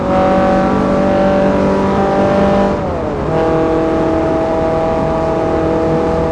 Index of /server/sound/vehicles/tdmcars/focussvt
fourth_cruise.wav